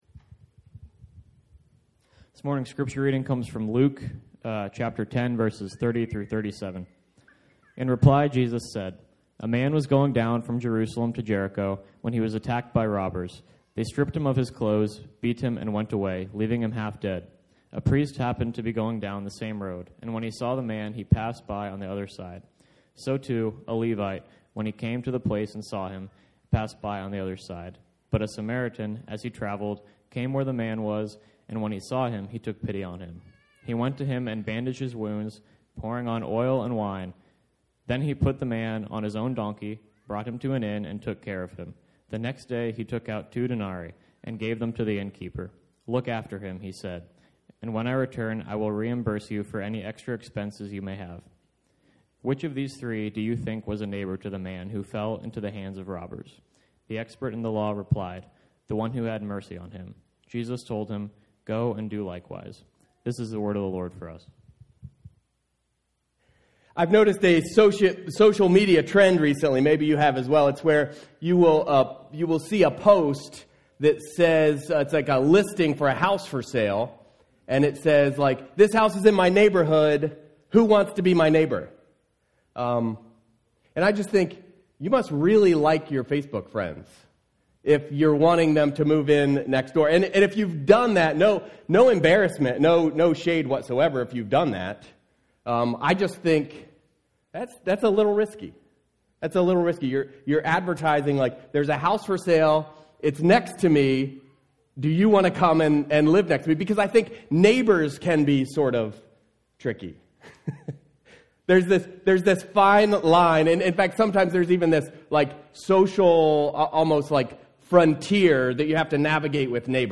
Sermons | Mennonite Christian Assembly